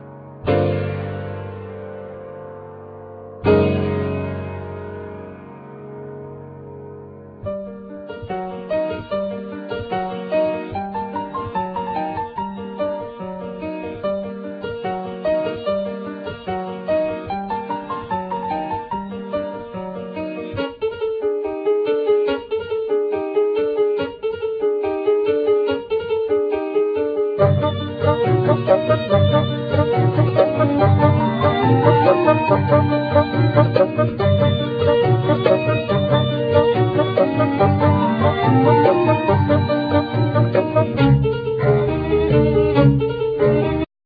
Violin
Bassoon
Piano
Double-Bass
Drums,Percussions
Clarinet
Strings Quartet
Organ